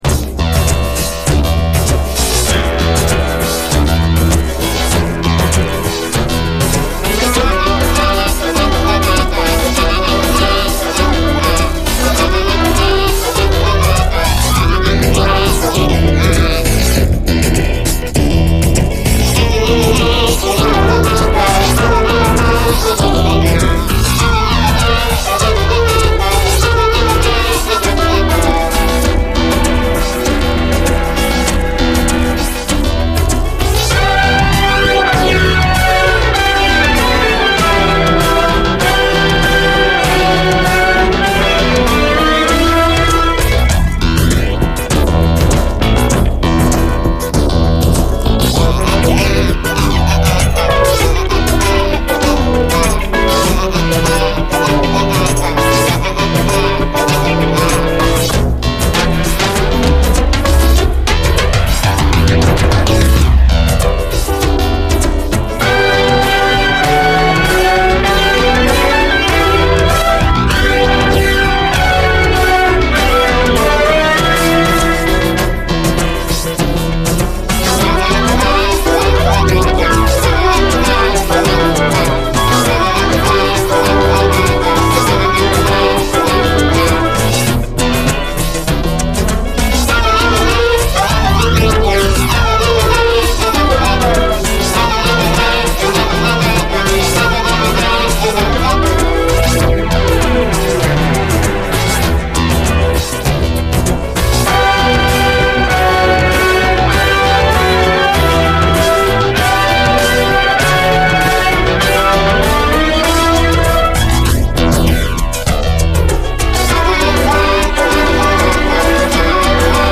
チュニジア出身、ドイツで活動した黒人ポップス系シンガーの1ST！